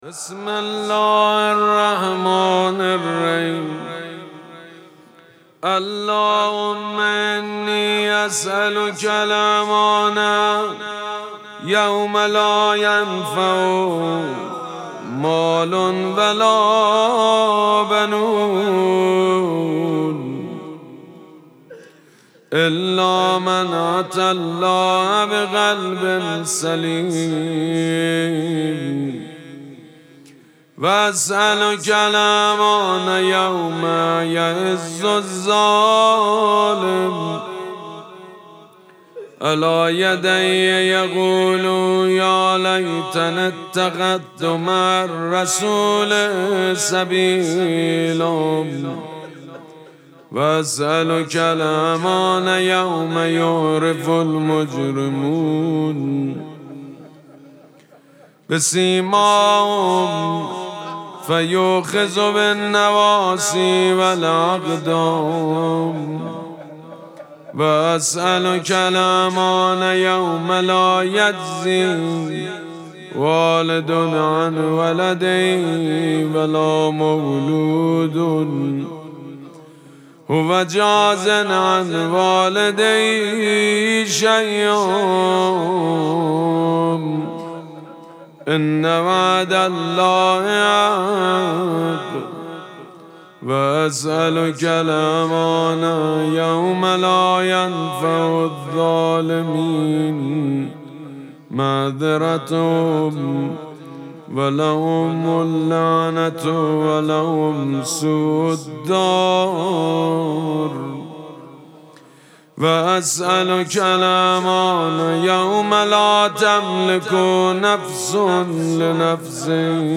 مراسم مناجات شب دوم ماه مبارک رمضان ‌‌‌‌یکشنبه ۱۲ اسفند ماه ۱۴۰۳ | ۱ رمضان ۱۴۴۶ ‌‌‌‌‌‌‌‌‌‌‌‌‌هیئت ریحانه الحسین سلام الله علیها
مداح حاج سید مجید بنی فاطمه